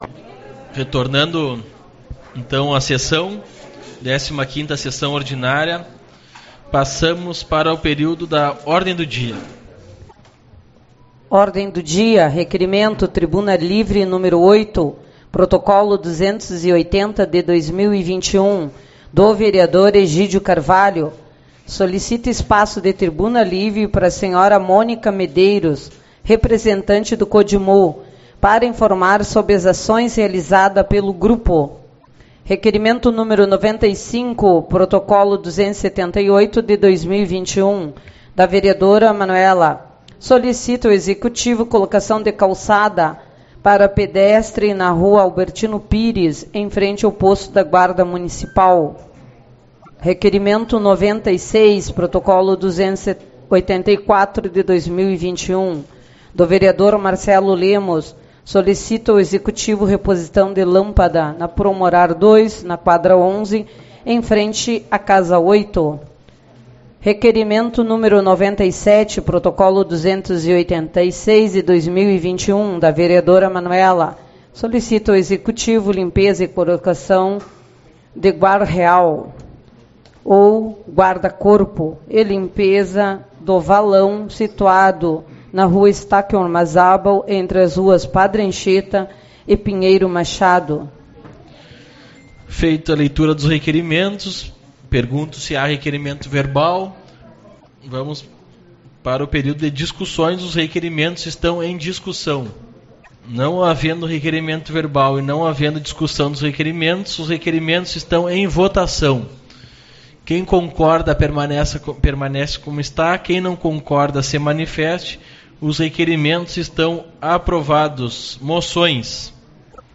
23/03 - Reunião Ordinária